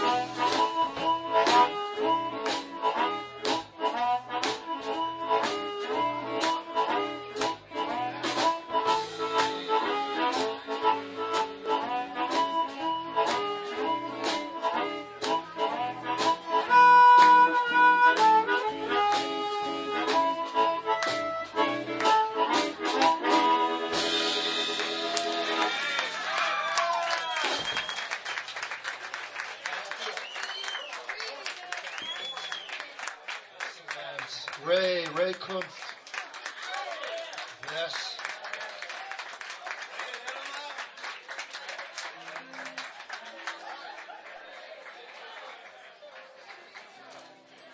5th OTS Recital - Winter 2005 - rjt_4171